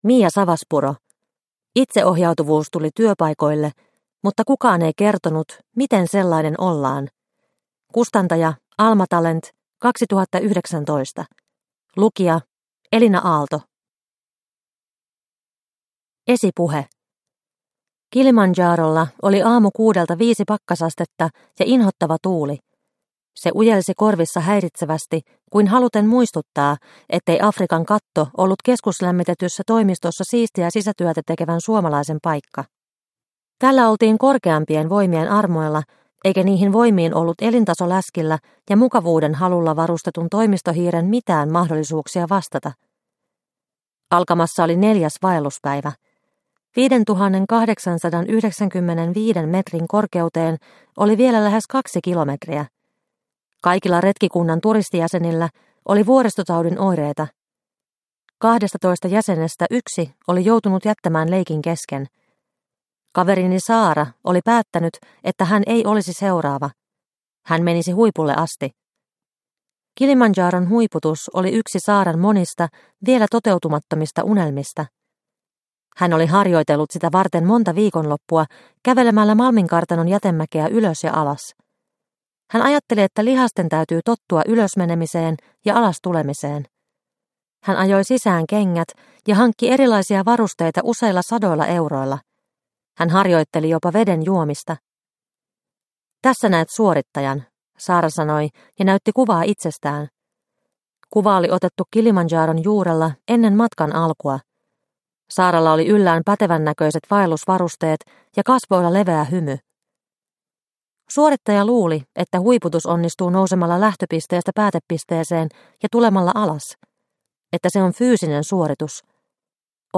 Itseohjautuvuus tuli työpaikoille – Ljudbok – Laddas ner